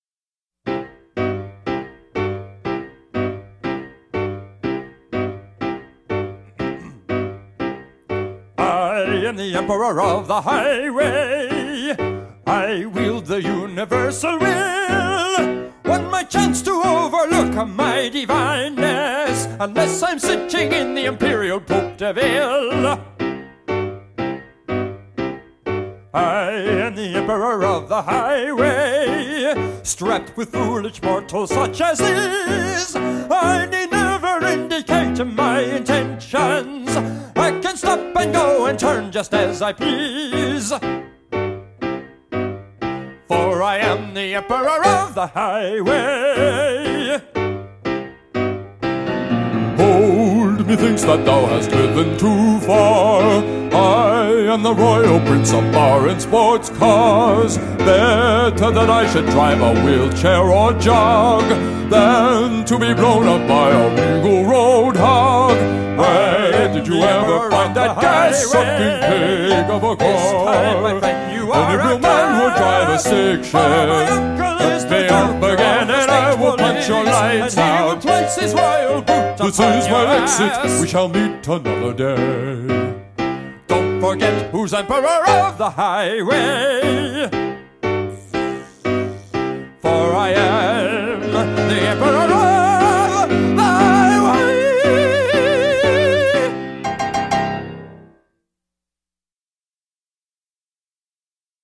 casts his ode to arrogant drivers as Operatic Duet